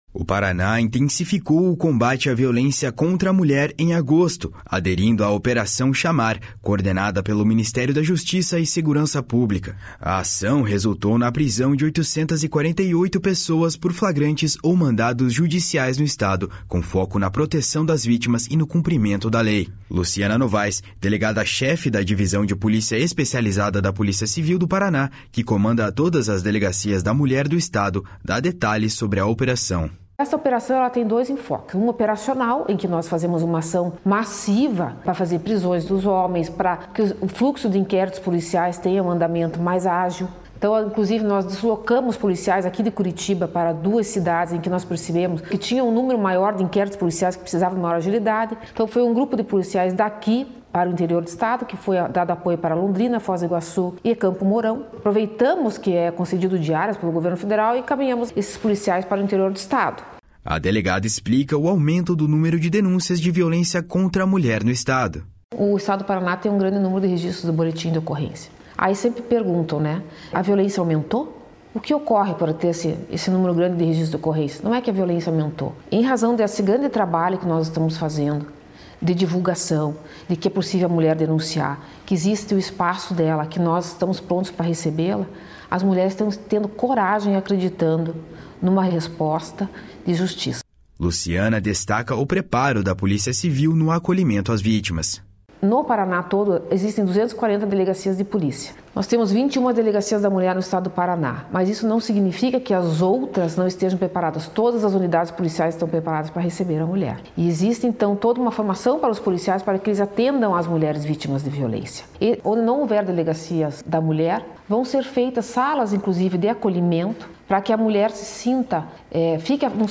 Delegada explica trabalho intenso e integrado do Paraná em defesa das mulheres